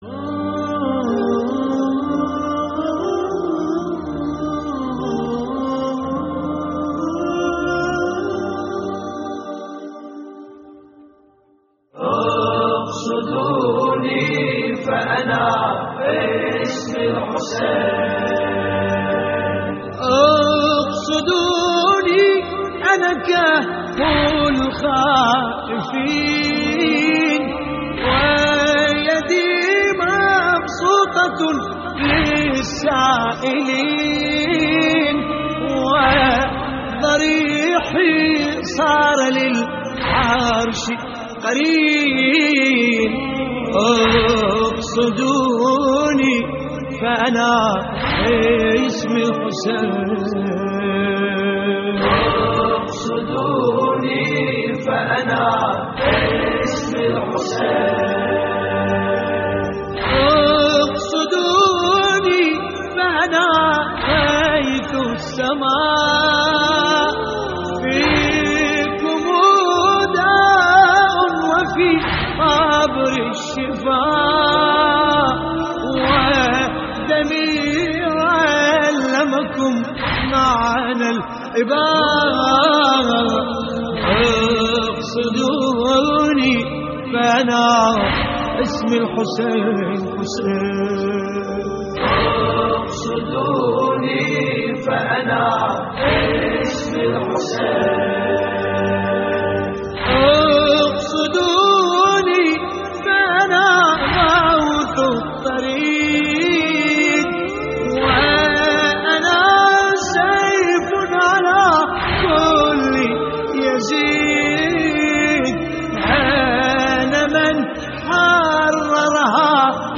اللطميات الحسينية
استديو «اقصدوني»